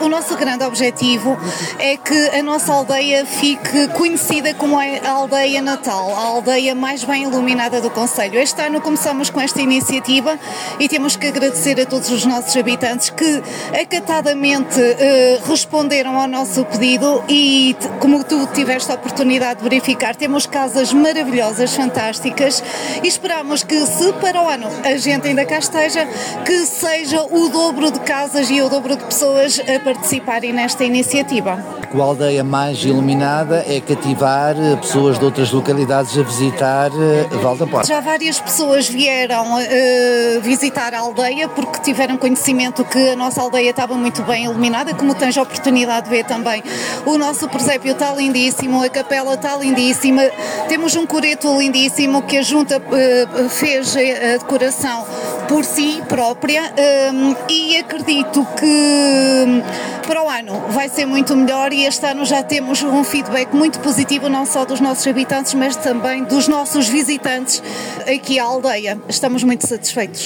Carla Miranda, a presidente de Junta de Freguesia de Vale da Porca, conta que o objetivo é transformar a aldeia mais iluminada para captar mais visitantes: